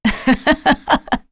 Seria-Vox_Happy2.wav